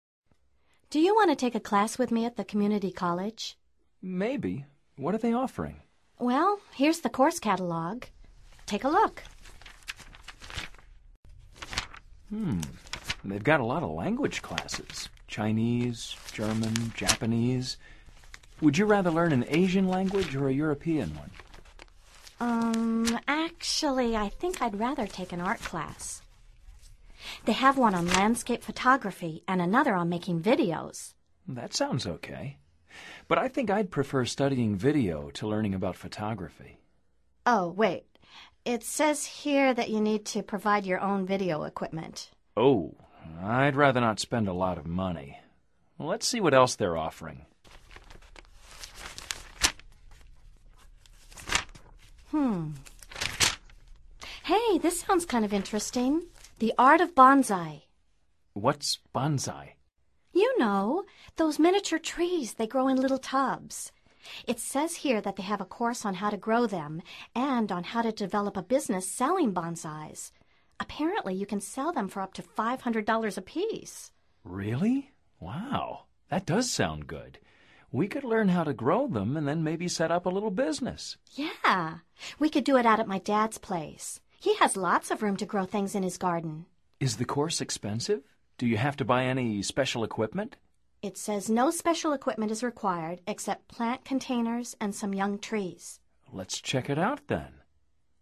Escucha el audio y concéntrate en la entonación y ritmo de las frases.